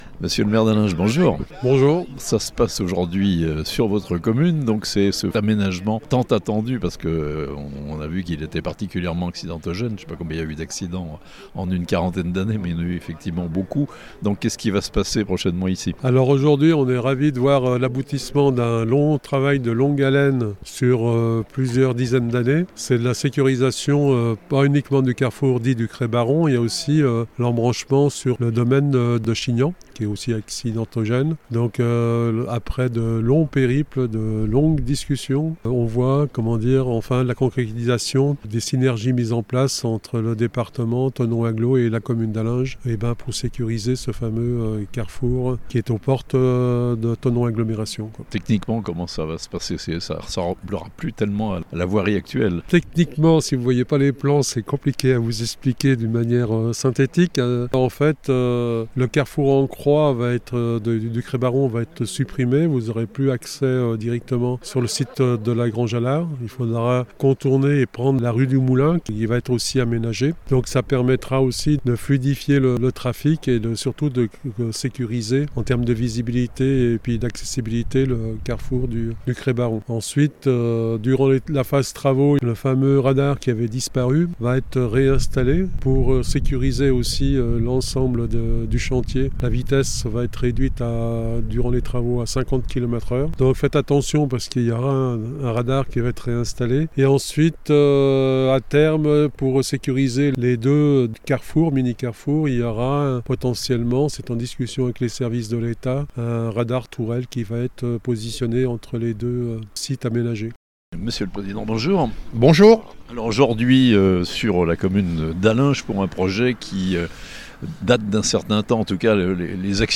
Aménagement du carrefour du Crêt Baron à Allinges : les travaux vont être lancés (interviews)